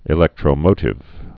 (ĭ-lĕktrō-mōtĭv)